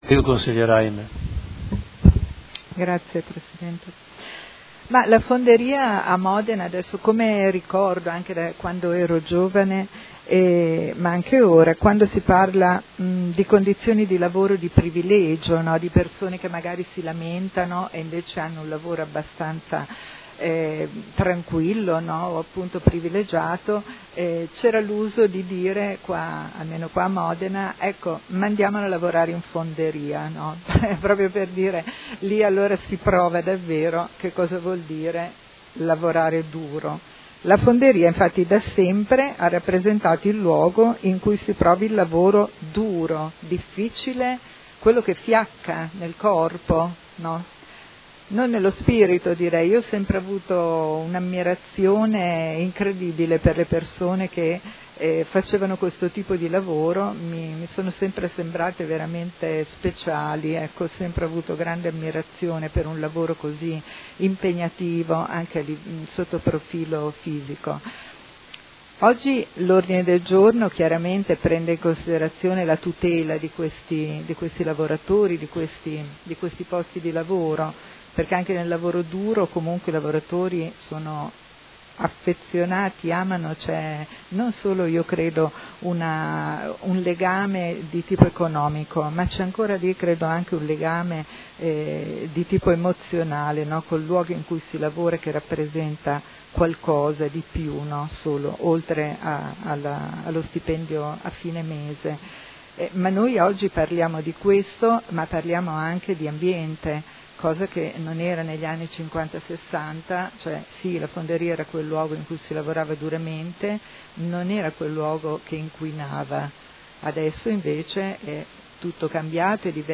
Paola Aime — Sito Audio Consiglio Comunale